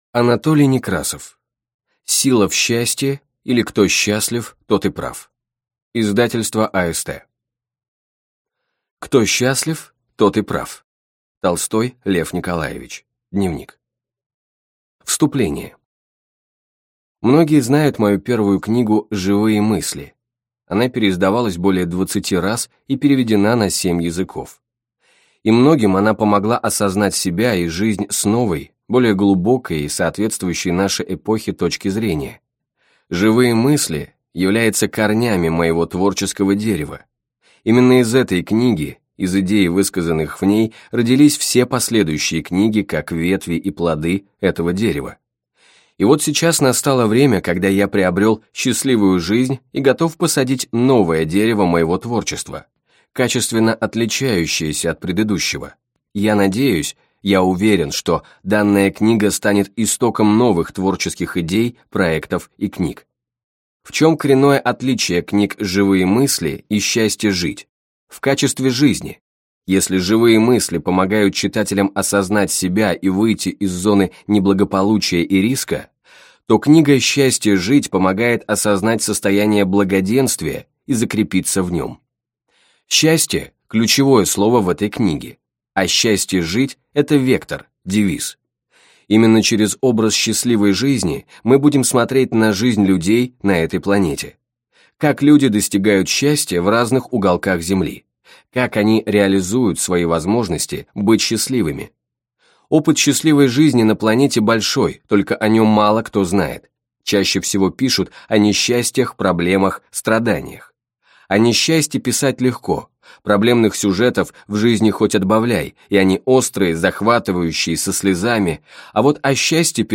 Аудиокнига Сила в счастье, или Кто счастлив, тот и прав | Библиотека аудиокниг